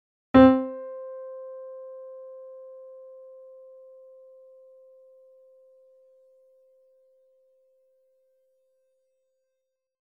音色：　モデリング音源コンサートピアノ　→